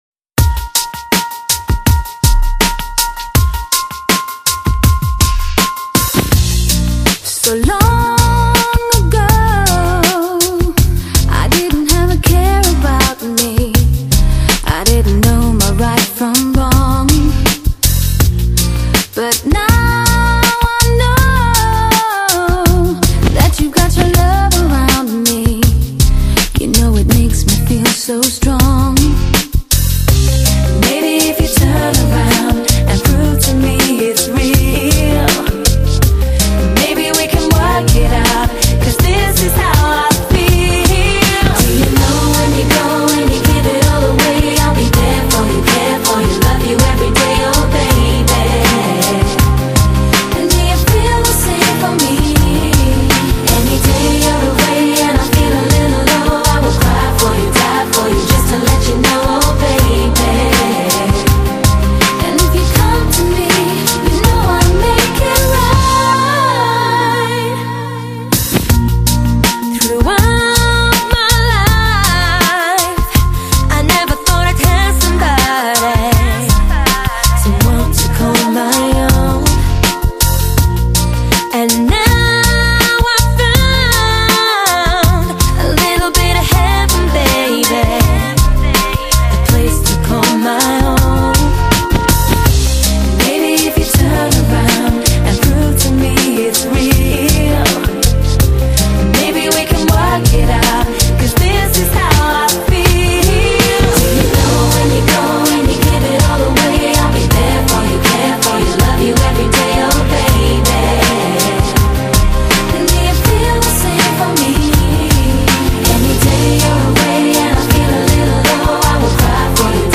劲爆动感节奏，首首旋律节拍简明轻快，融入灵魂的性感音乐